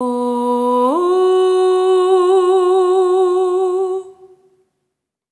SOP5TH B3 -R.wav